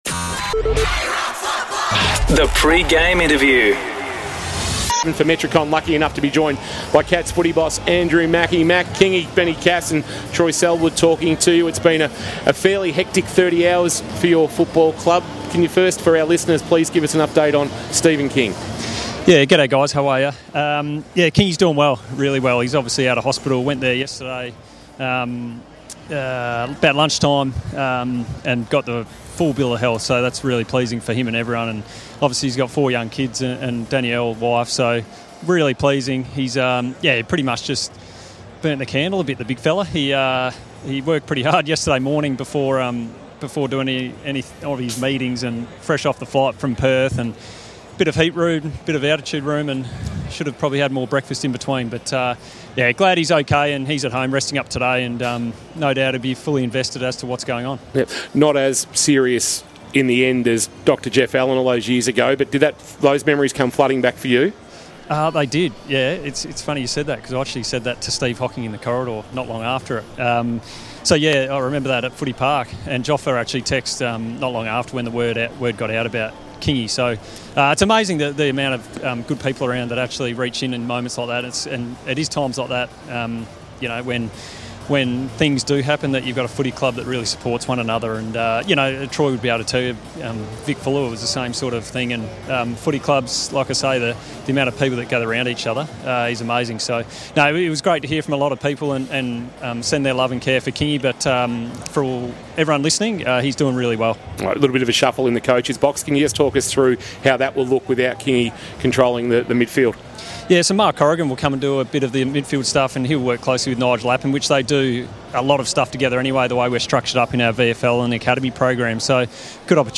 2024 - AFL - Preliminary Final - Geelong vs. Brisbane: Pre-match interview